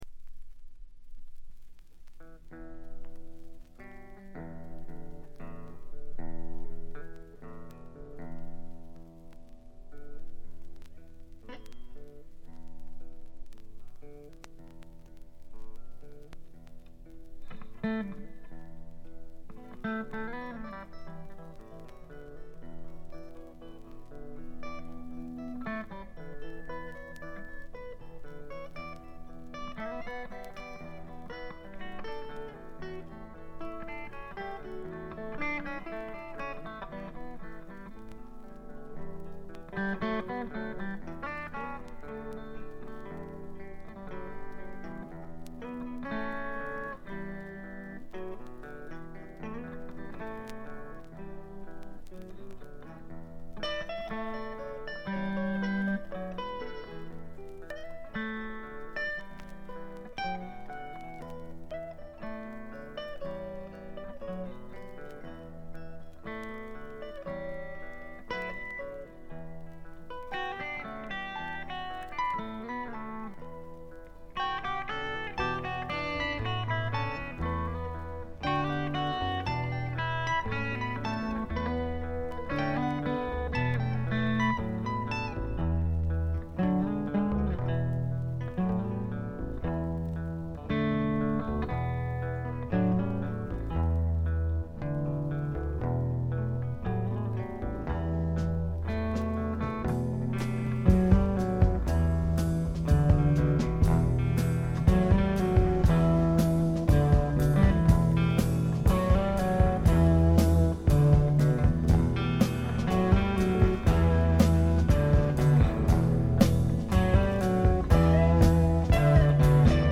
静音部で軽微なバックグラウンドノイズや軽微なチリプチ少々。
試聴曲は現品からの取り込み音源です。